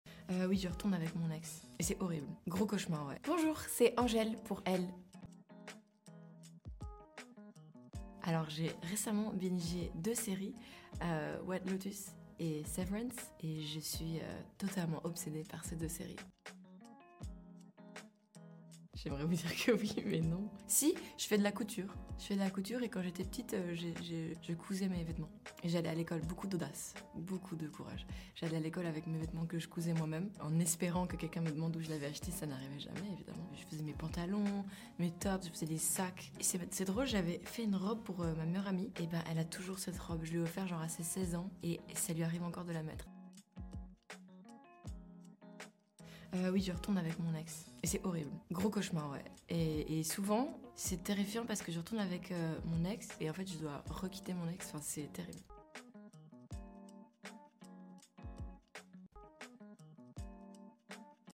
Angèle dans une récente interview sound effects free download